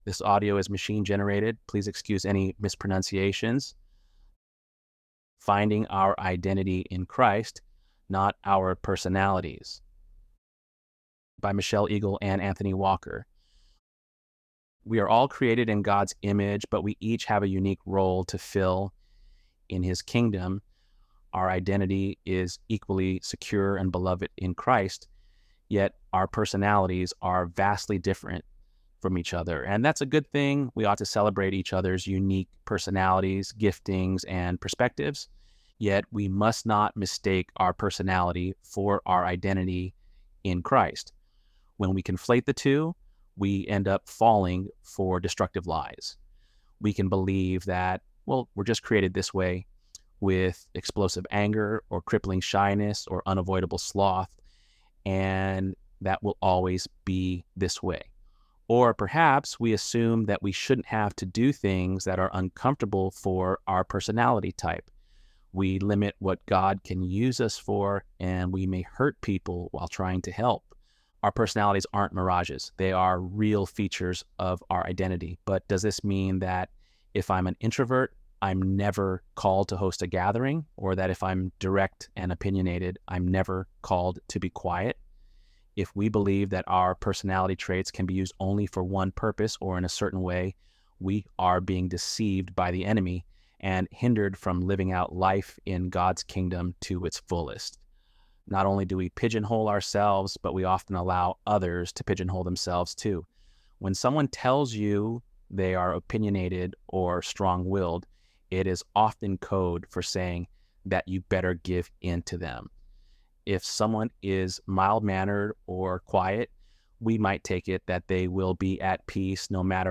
ElevenLabs_10.8.mp3